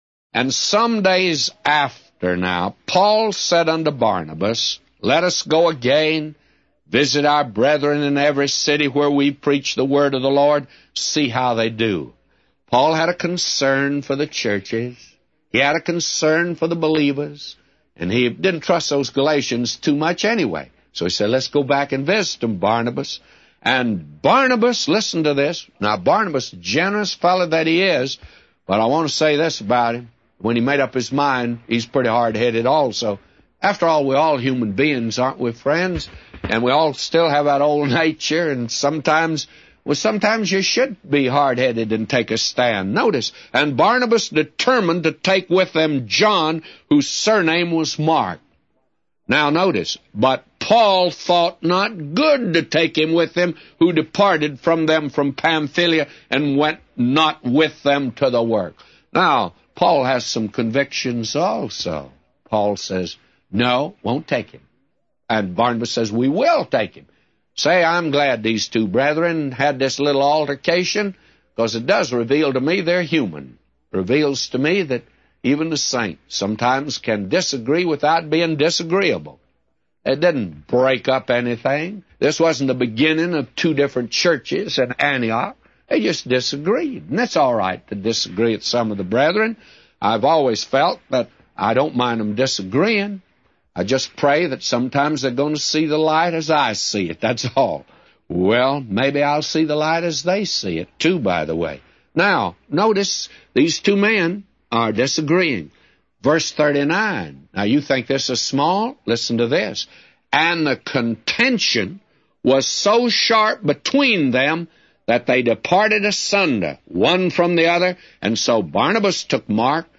Morning Bible Reading - Job 41